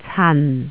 「長洲」，它的坊間拼法是〔cheung chau〕。雖然兩個字都是以〔ch〕開首，但只要仔細聽的話，你便會發現它們的聲母並不一樣。